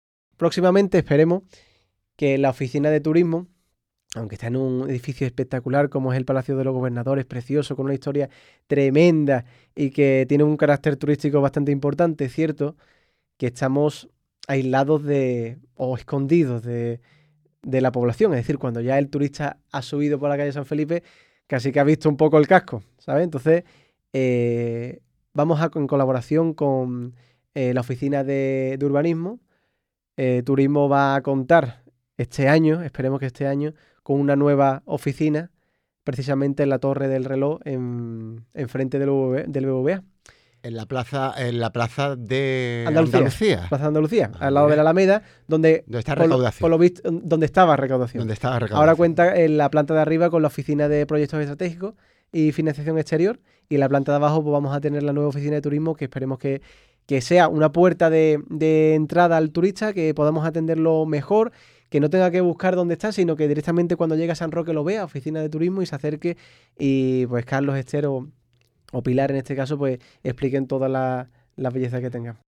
Valdivia anuncia en la radio el traslado de la Oficina de Turismo a la Plaza de Andalucía